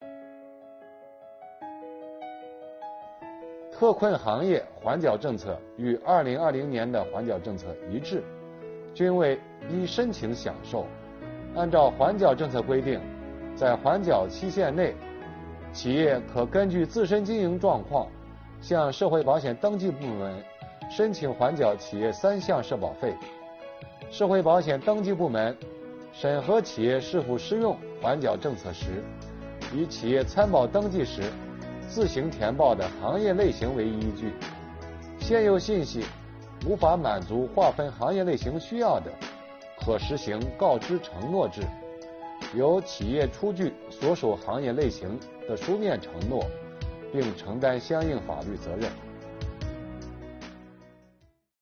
近日，国家税务总局推出新一批“税务讲堂”系列课程，为纳税人缴费人集中解读实施新的组合式税费支持政策。本期课程由国家税务总局社会保险费司副司长王发运担任主讲人，对公众关注的特困行业阶段性缓缴企业社保费政策问题进行讲解。